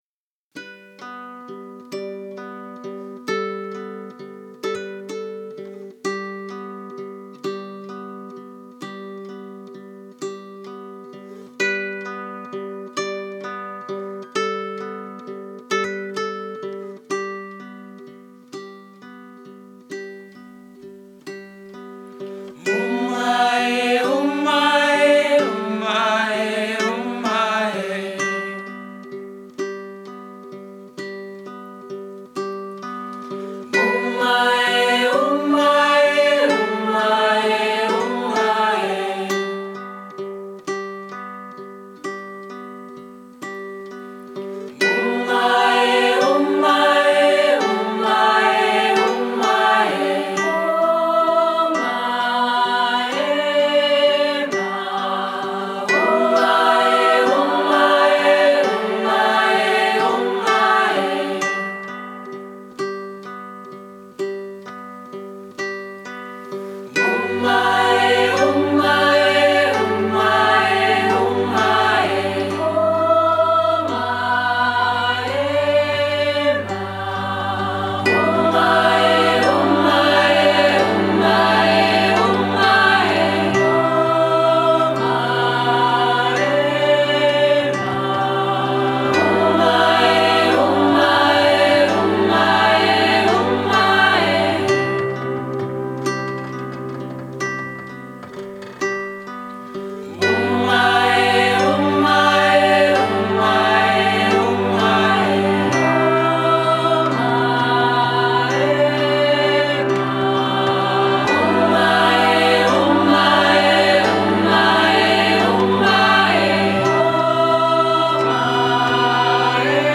spare, chilly, gorgeous